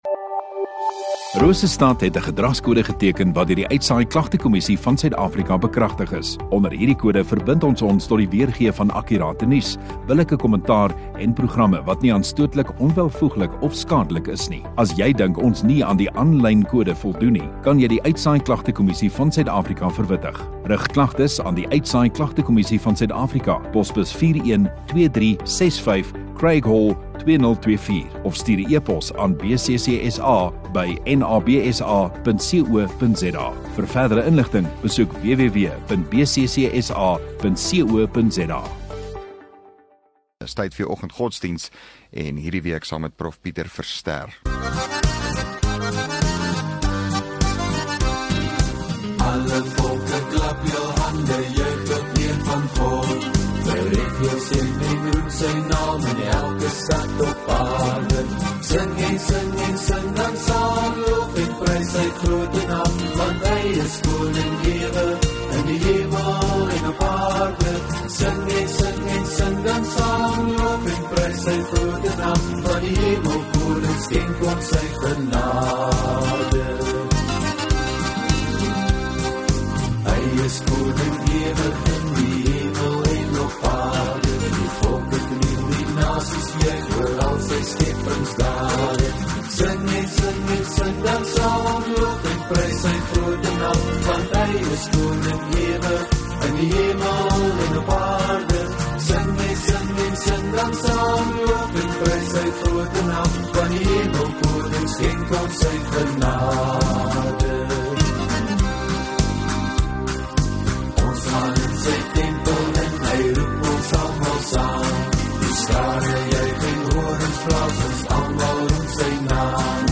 1 May Donderdag Oggenddiens